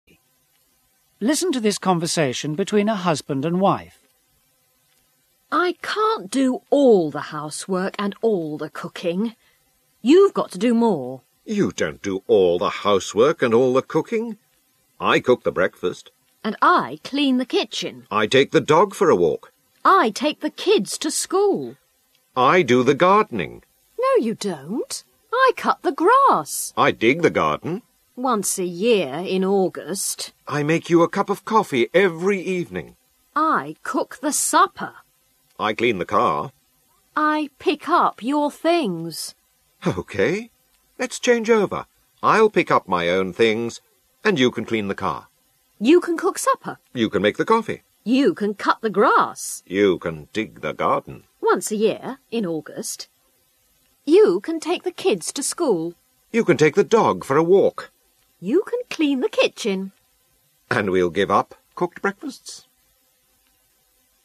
SE Unit 10 dialogue k-g.mp3